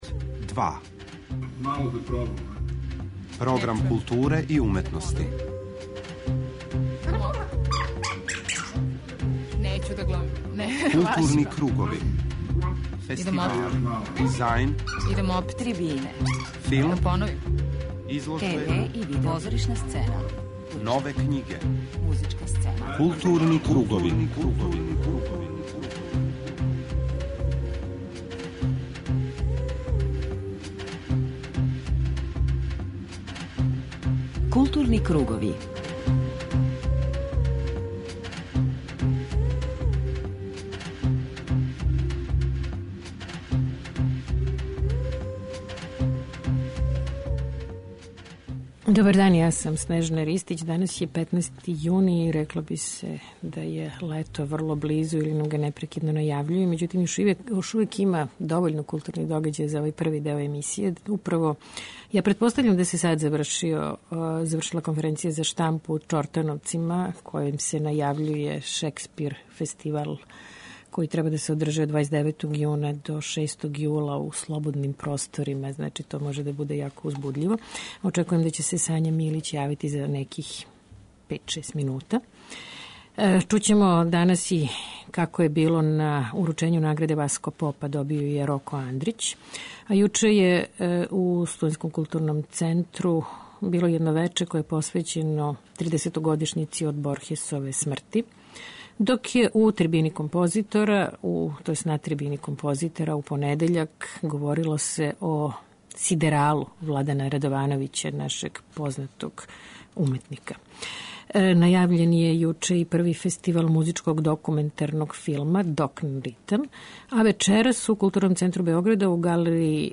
преузми : 41.00 MB Културни кругови Autor: Група аутора Централна културно-уметничка емисија Радио Београда 2.